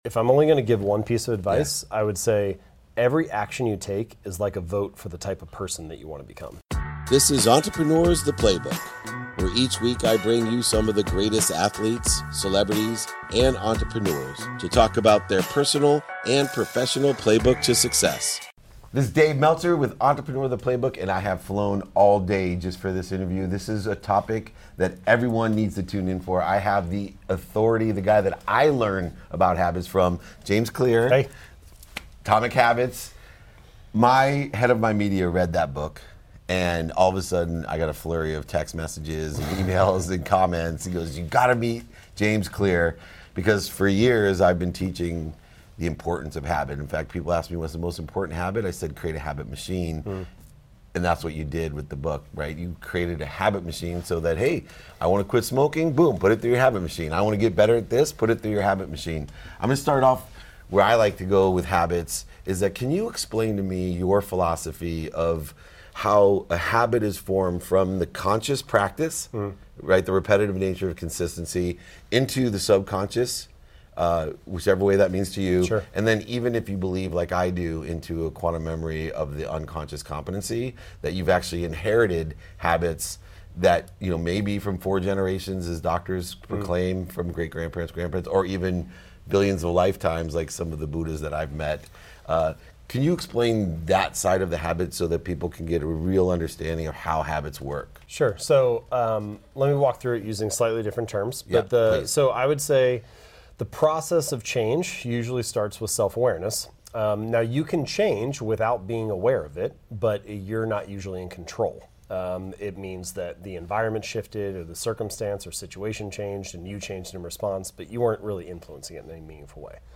Today’s episode is from a conversation I had back in 2019 with James Clear, writer, speaker, and the best-selling author of Atomic Habits. We took a deep dive into the essence of habit formation, discussing the significance of self-awareness, the process of transitioning from conscious to subconscious habits, and the influence of genetics on our behavior. James emphasizes that every action we take is a vote for the person we aspire to be, highlighting the power of identity in shaping our actions.